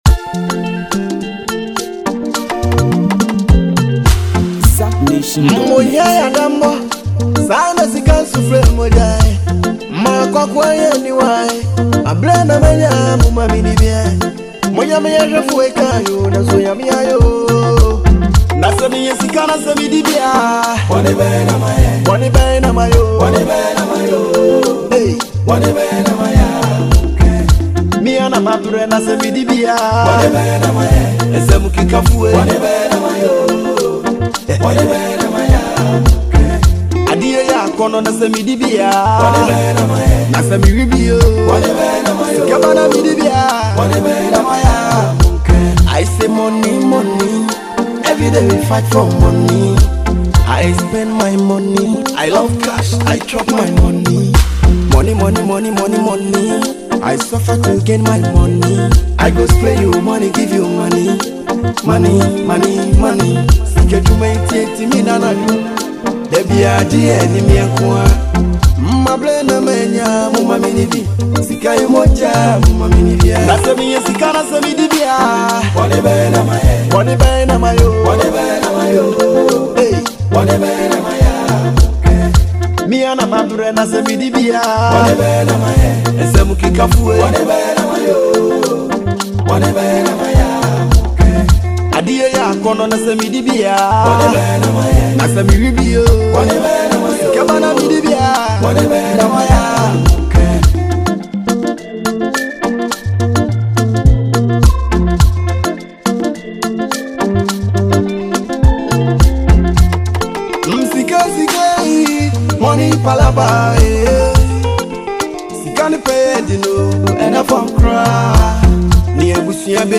hip-hop and Afrobeat